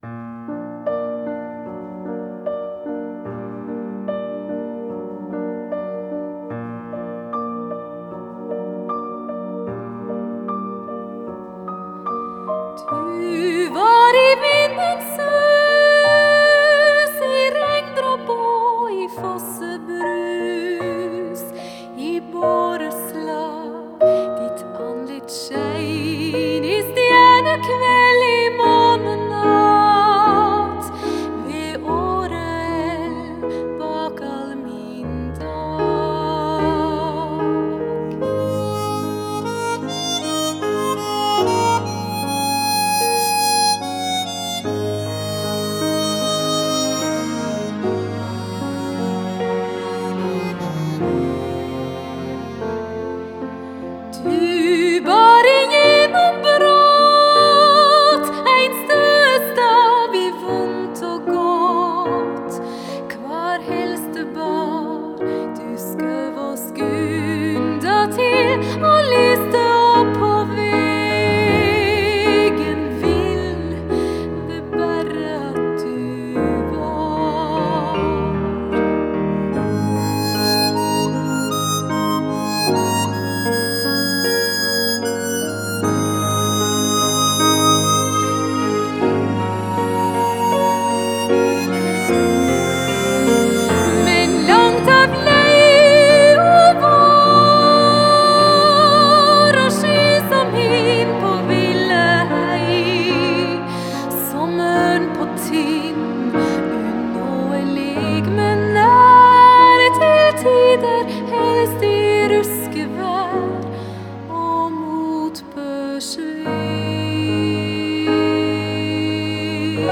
口琴演奏